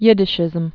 (yĭdĭ-shĭzəm)